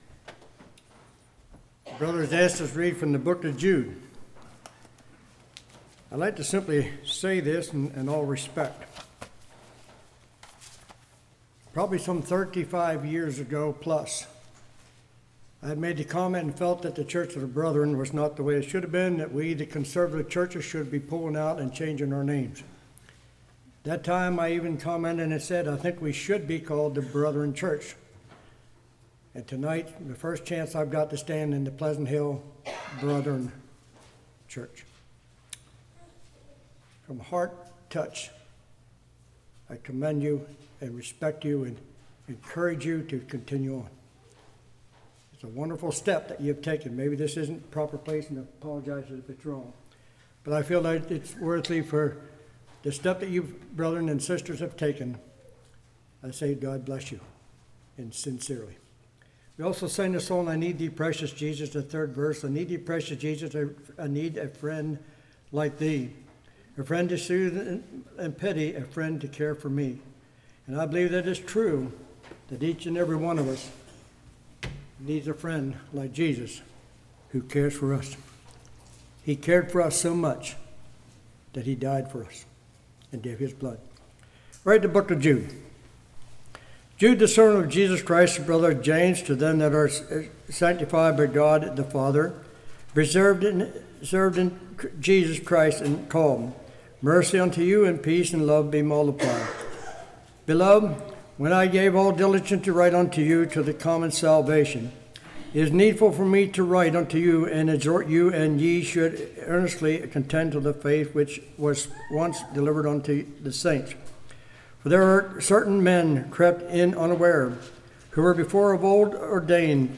Passage: Jude 1-25 Service Type: Evening Some Were Once Saved Some Were Never Saved Sound Doctrine Exposes False Doctrine How Do we Know The Difference?